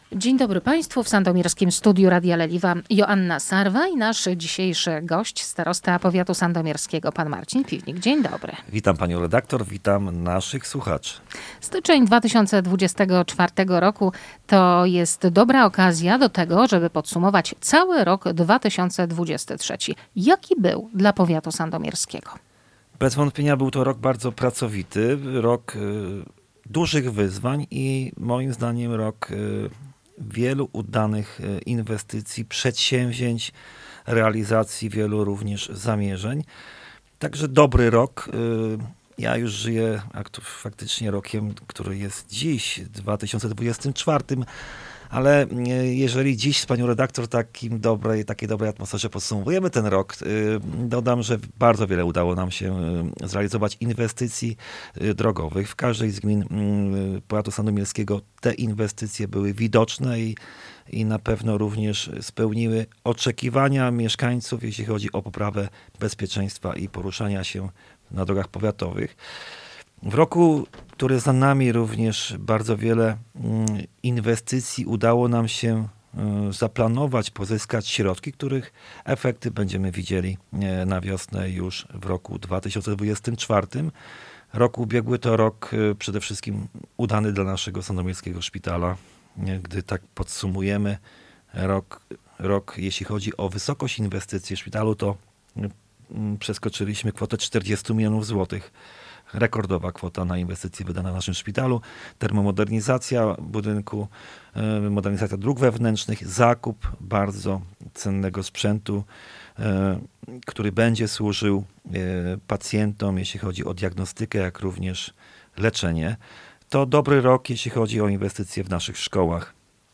'- To był dobry rok dla powiatu - podsumowuje 2023 rok Gość Radia Leliwa - starosta powiatu sandomierskiego Marcin Piwnik.